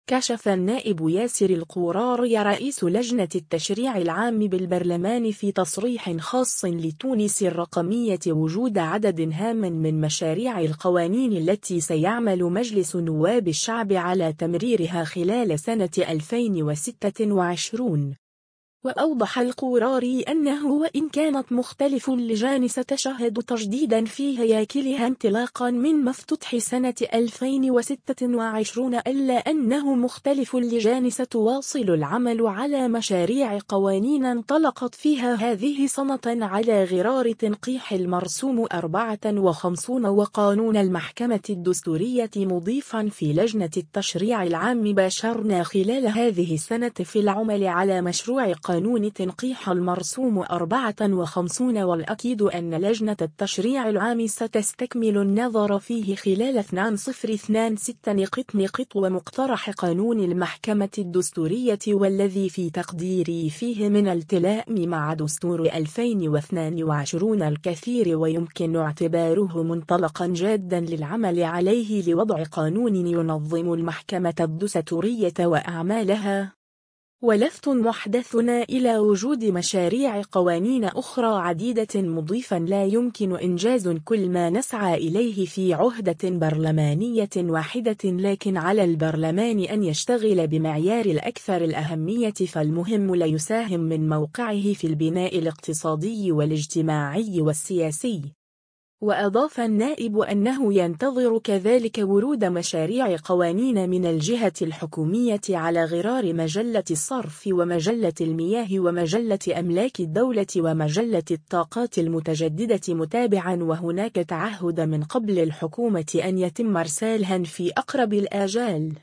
كشف النائب ياسر القوراري رئيس لجنة التشريع العام بالبرلمان في تصريح خاص لـ”تونس الرقمية” وجود عدد هام من مشاريع القوانين التي سيعمل مجلس نواب الشعب على تمريرها خلال سنة 2026.